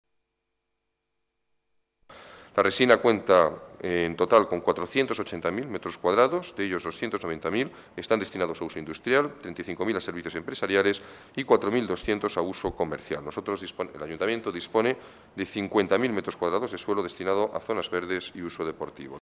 Nueva ventana:El Alcalde en funciones describe la nueva estación de Inspección Técnica de Vehículos en el polígono industrial La Resina, dentro del distrito de Villaverde